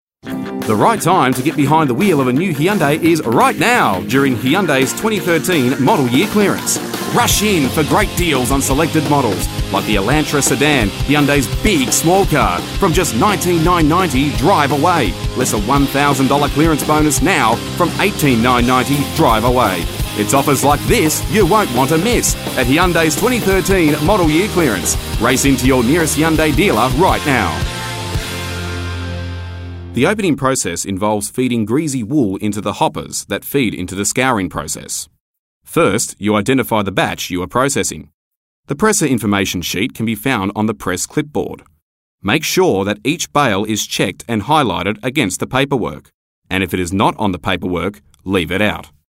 Radio & TV Commercial Voice Overs Talent, Artists & Actors
Yng Adult (18-29) | Adult (30-50)